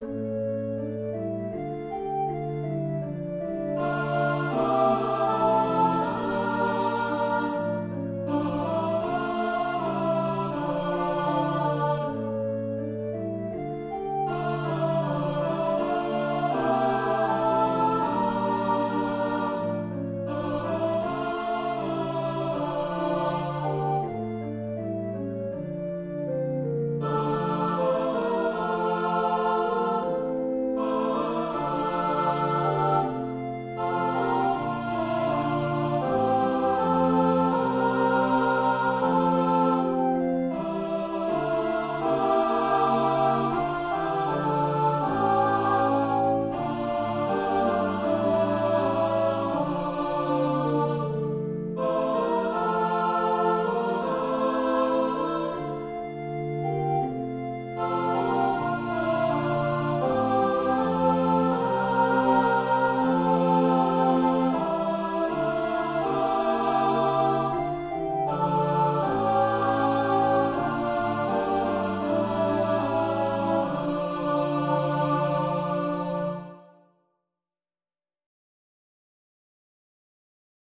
Voicing/Instrumentation: SAB , Organ/Organ Accompaniment